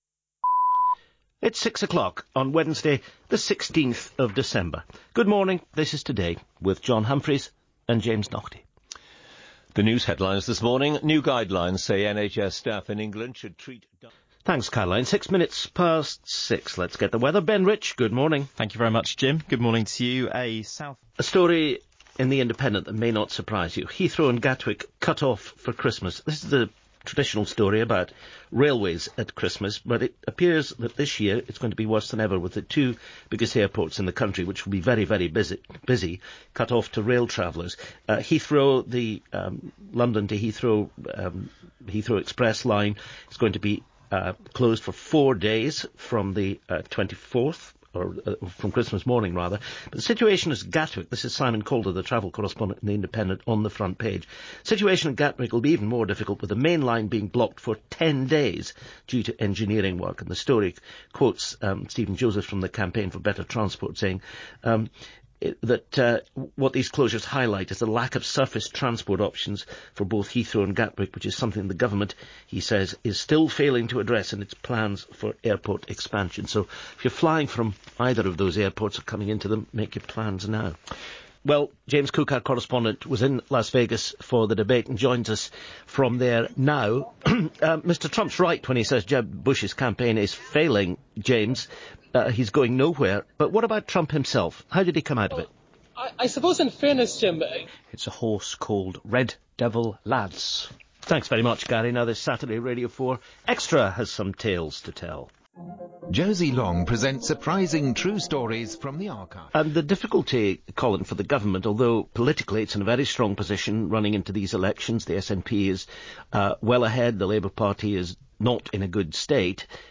Calm, measured, looking around with care for the next word under the settee, James Naughtie has been part of Radio 4’s Today programme for 21years.
Enjoy here an unfairly telescoped edition of a programme which should never be thus edited.